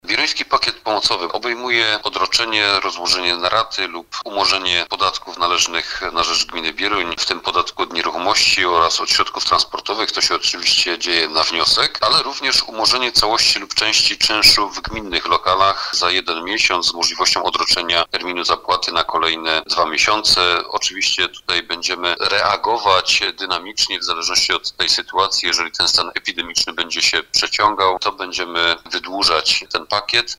- Przedsiębiorcy mogą liczyć na umorzenie całości bądź części czynszu w gminnych lokalach za 1 miesiąc, z możliwością odroczenia terminu zapłaty czynszu na kolejne 2 miesiące - mówi burmistrz Bierunia, Krystian Grzesica.